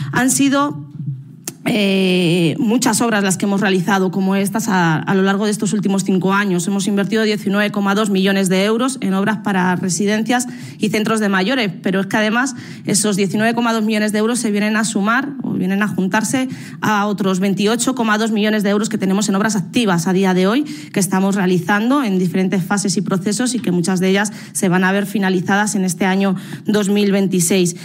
La consejera de Bienestar Social, Bárbara García Torijano, ha explicado en la inauguración de la reforma de la residencia de Mayores ‘Barber’ y el Centro de Mayores ‘Toledo II’